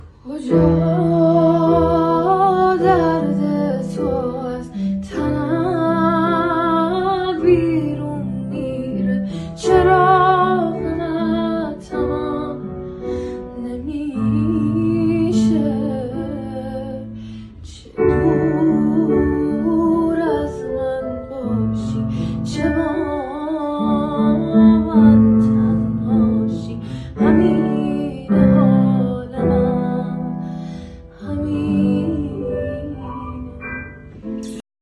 ژانر: پاپ
💔 فضایی احساسی و دلنشین